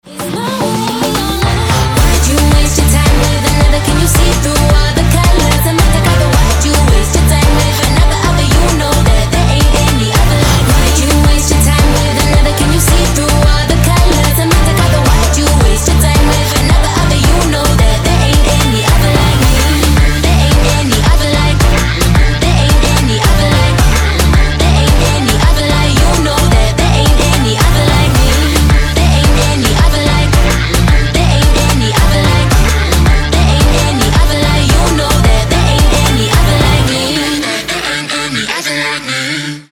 • Качество: 320, Stereo
поп
женский вокал
dance
Electronic
club
Electropop